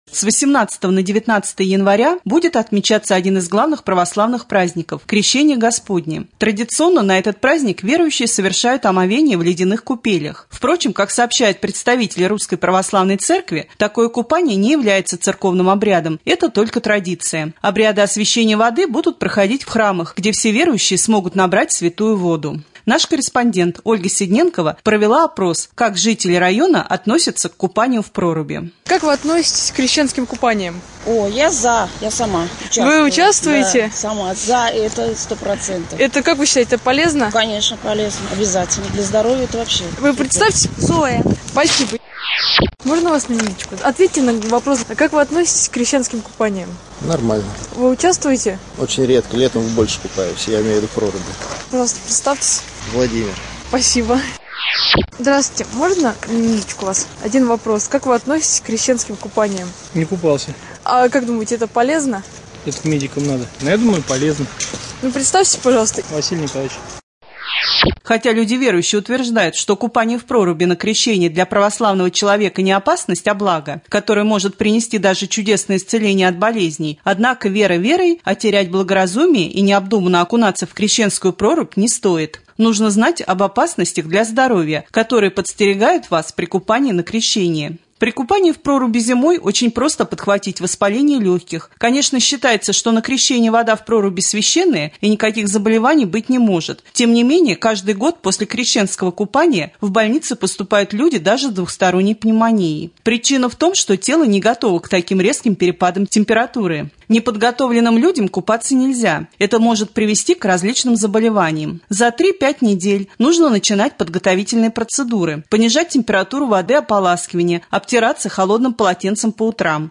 3.Опрос.mp3